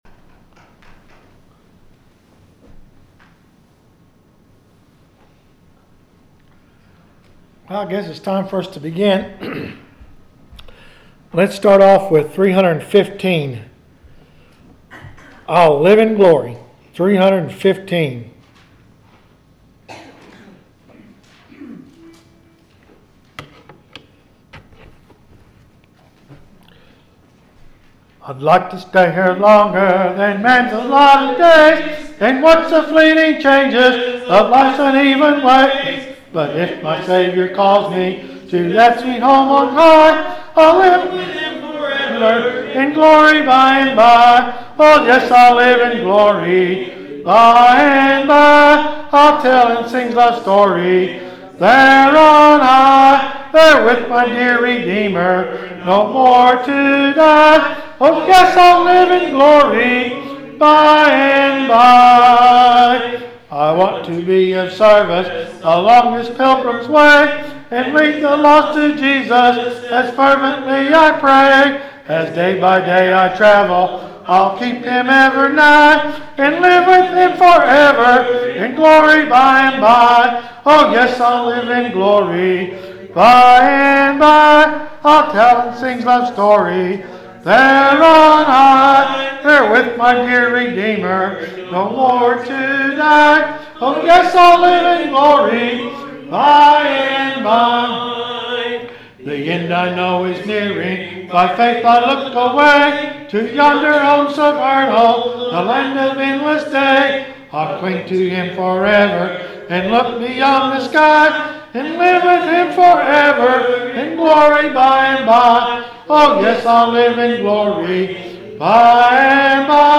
The sermon is from our live stream on 7/16/2025